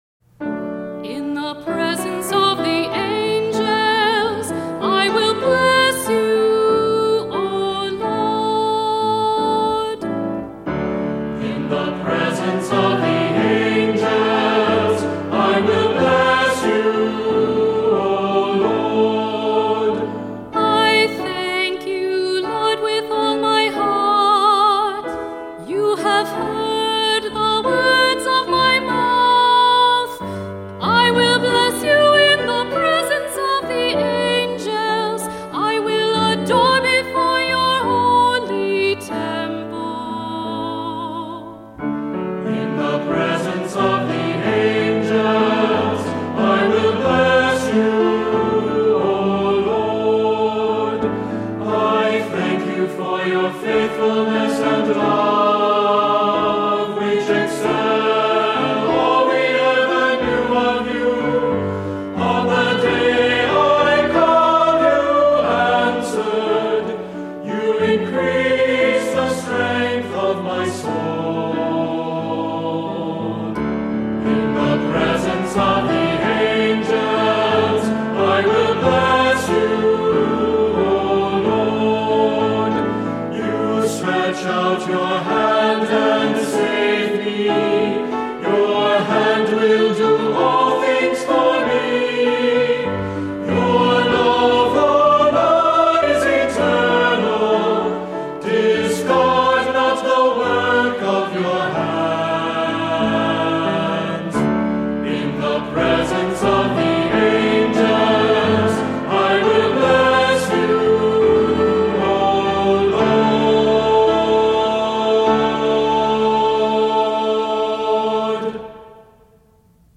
Voicing: Assembly, cantor